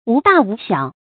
無大無小 注音： ㄨˊ ㄉㄚˋ ㄨˊ ㄒㄧㄠˇ 讀音讀法： 意思解釋： ①無論大小。